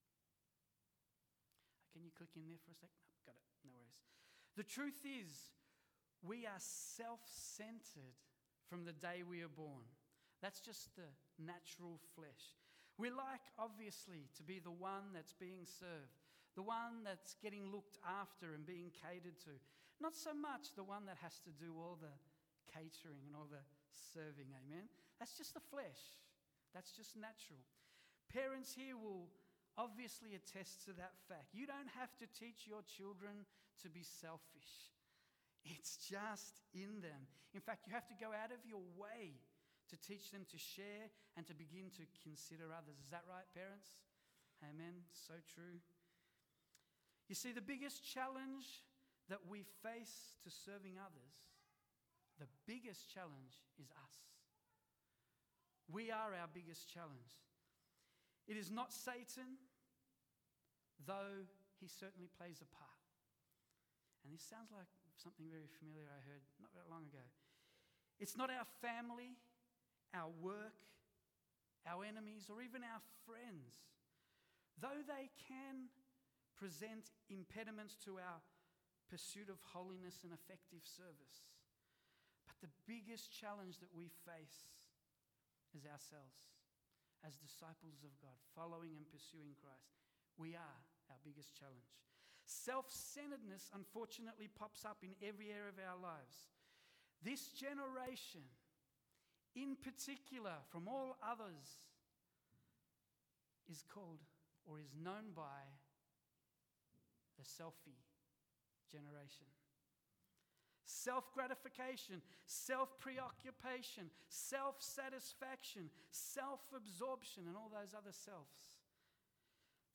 English Sermons | Casey Life International Church (CLIC)
English Worship Service - 25th September 2022